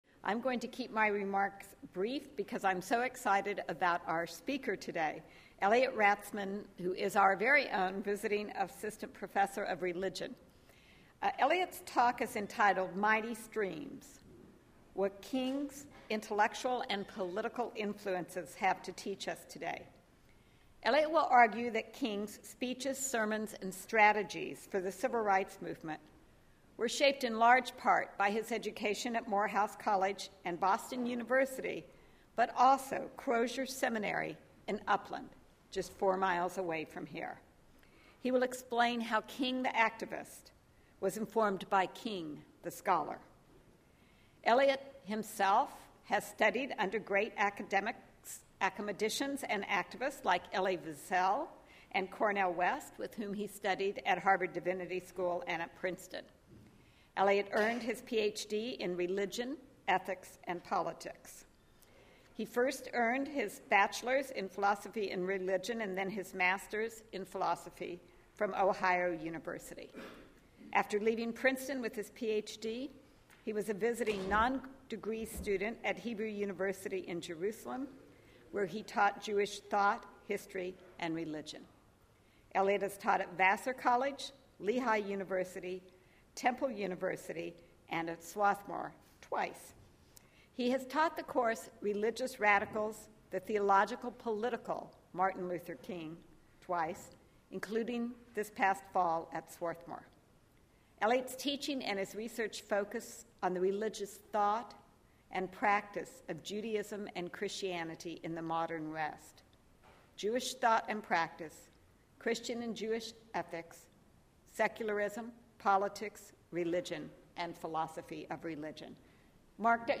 Faculty Lecture